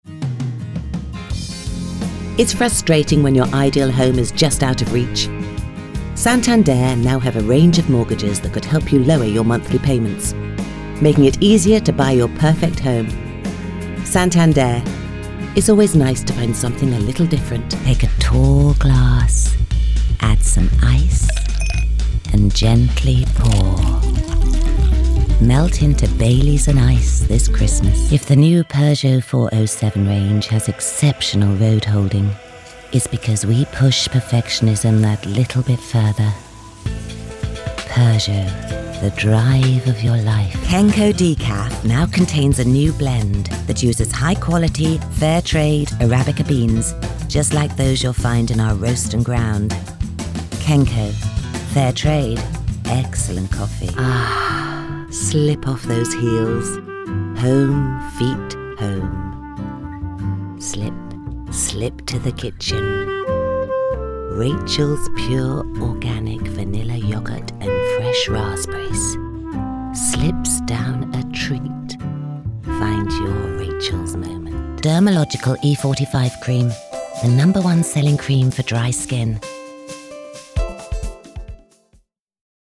Commercial showreel
new-commercial-reel-1.mp3